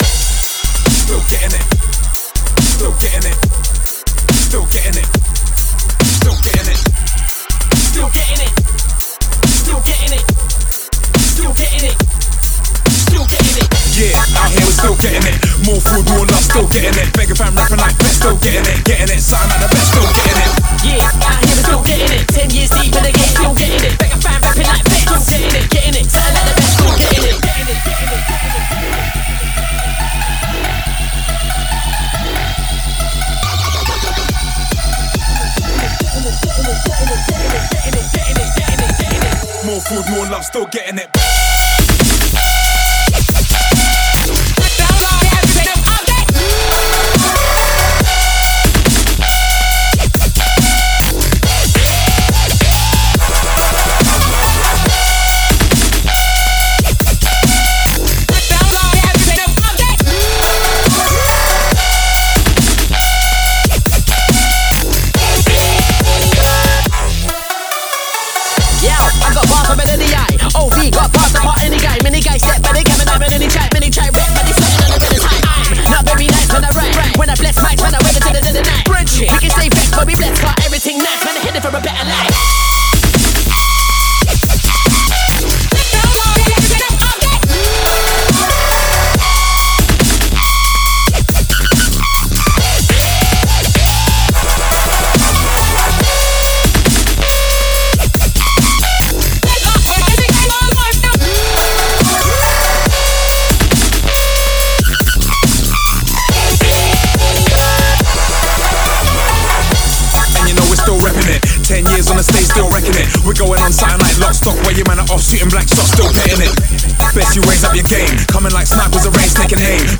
DUB STEP--> [6]